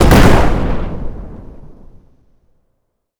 explosion_large_09.wav